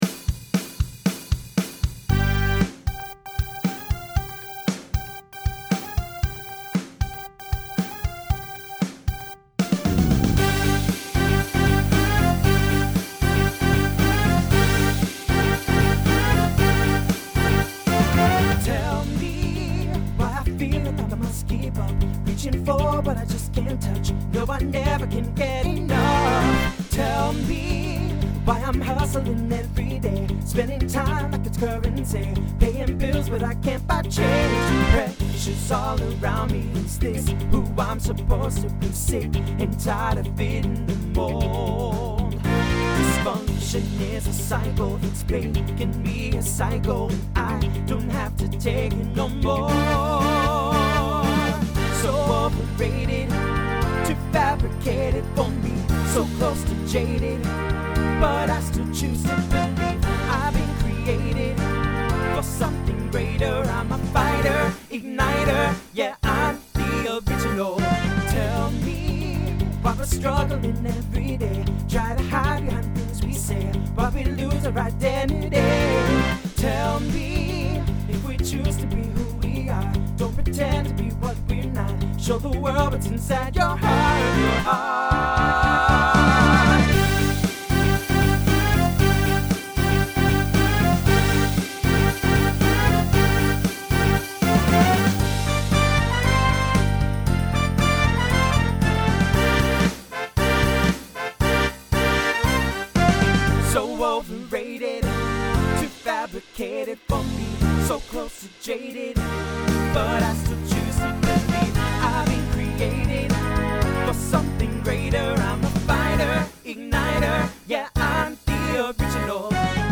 Voicing TTB Instrumental combo Genre Pop/Dance